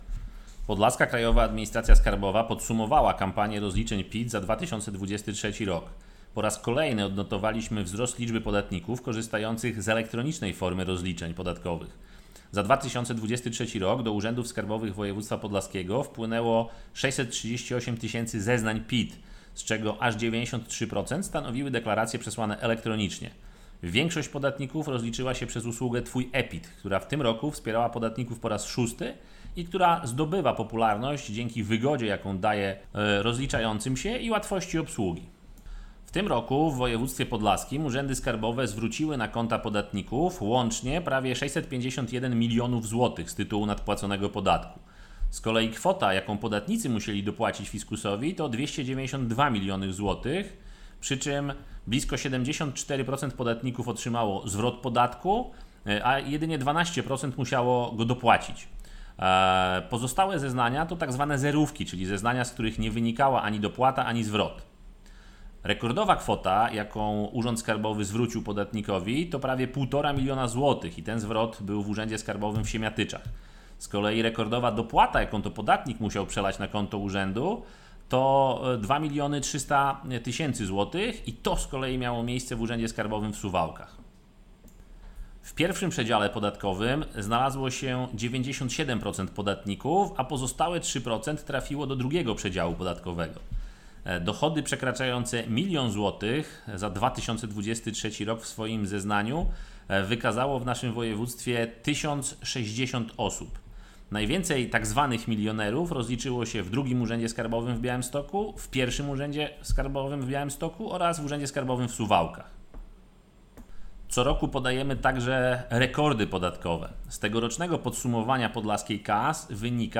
Podsumowanie PIT za 2023 r. - wypowiedź